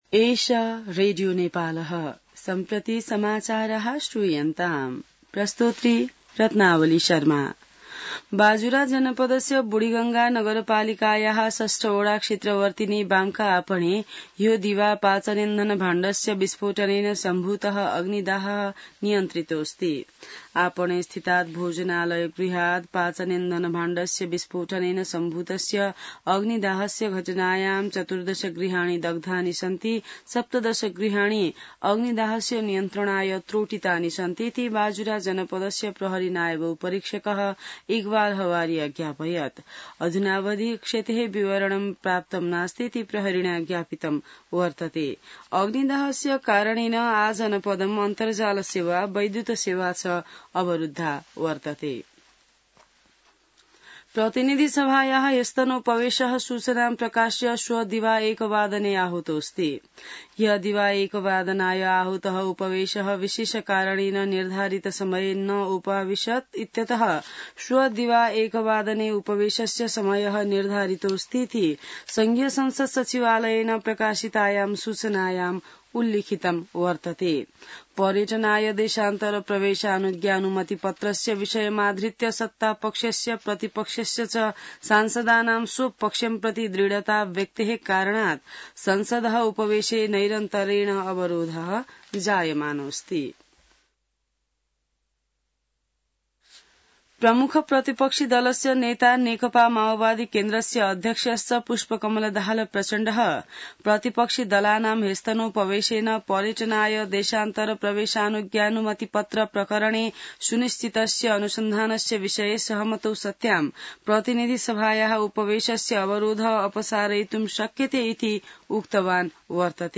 संस्कृत समाचार : २४ जेठ , २०८२